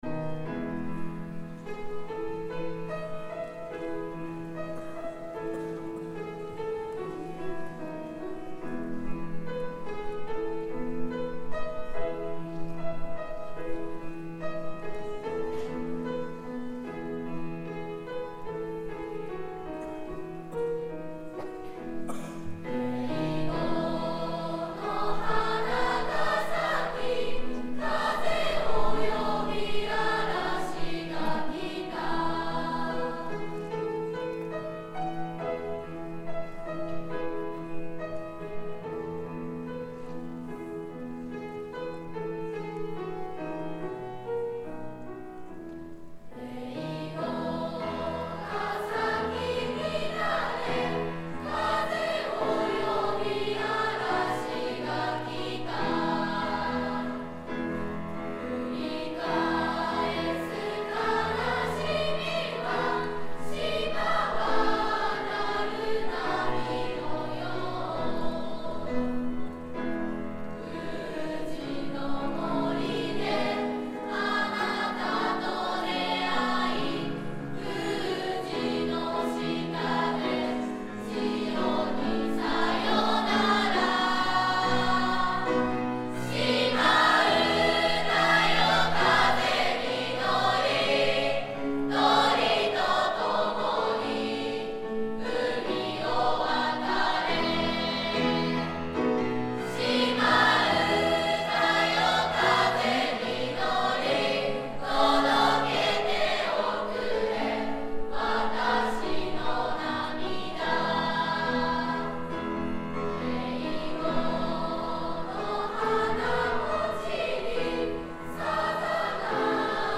２Ｈ 島唄.mp3 ←クリックすると合唱が聴けます